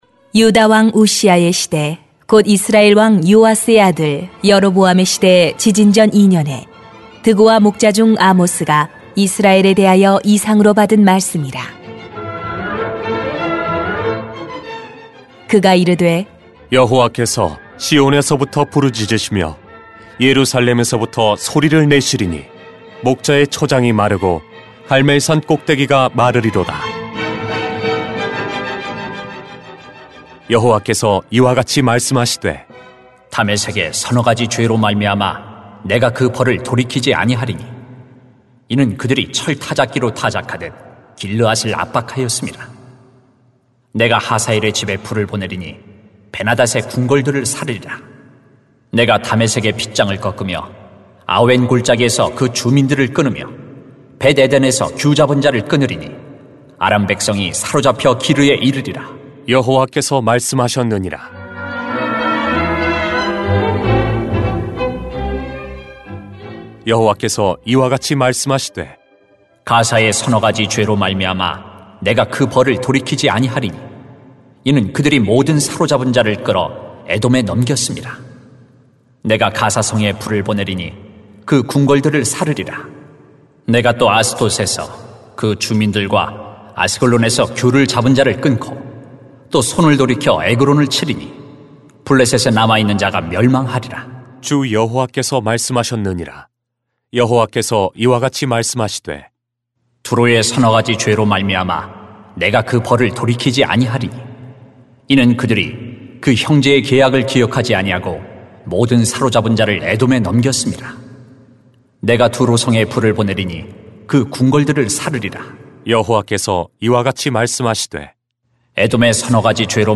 [암 1:1-2:3] 하나님이 사자처럼 부르짖습니다 > 새벽기도회 | 전주제자교회